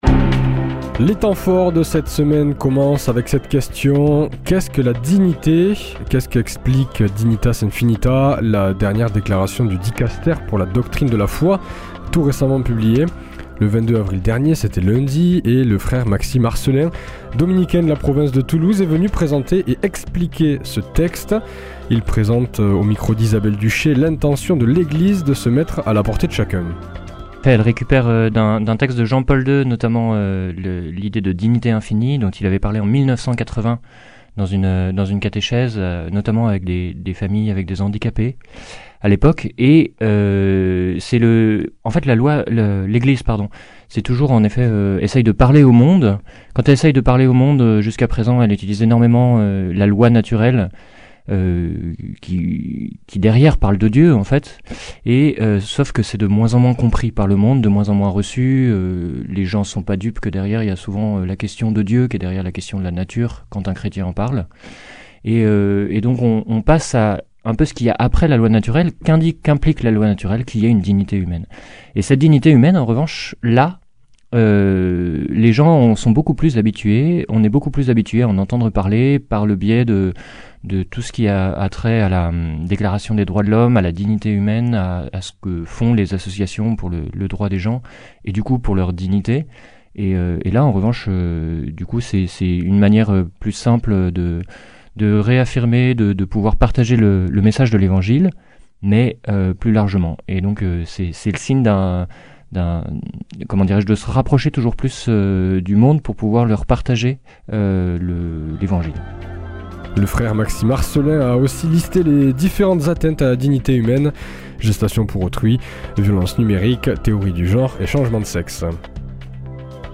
vendredi 26 avril 2024 Le grand entretien Durée 11 min
Journalistes